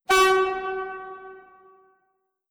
horn.wav